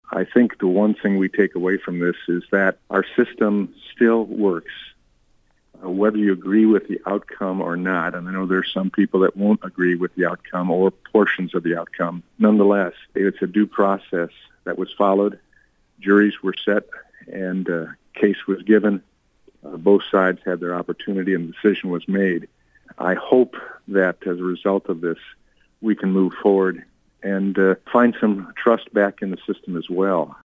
On a recent 7:40am break, Congressman Tim Walberg gave his reaction to the verdict. He talked to WLEN about the work of the judicial system…